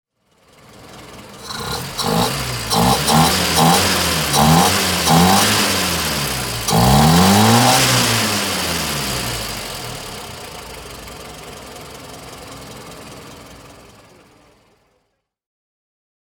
Praga Piccolo (1939) - Leerlauf
Praga_Piccolo_1939.mp3